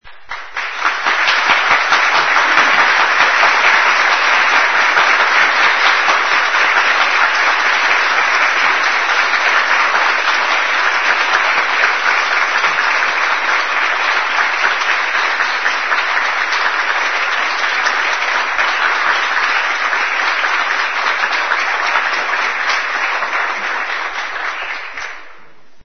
APLAUSOS APPLAUSE
Ambient sound effects
aplausos__APPLAUSE.mp3